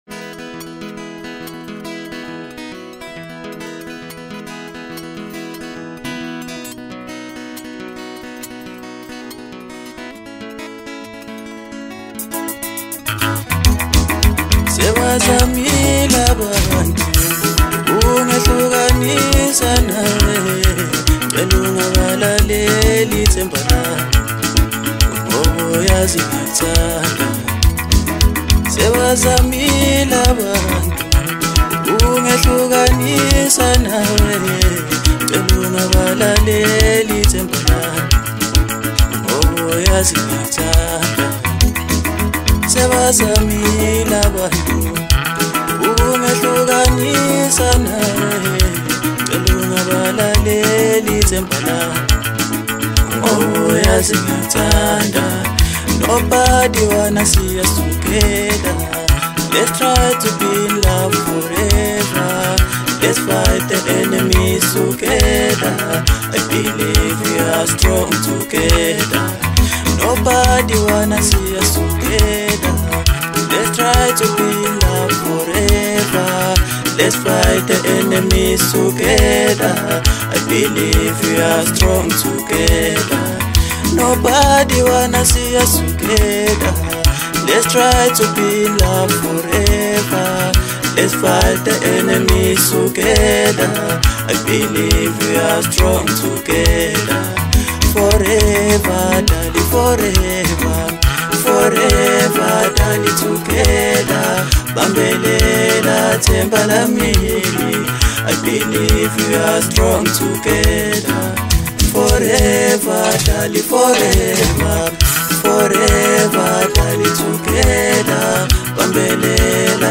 Home » Maskandi » DJ Mix » Hip Hop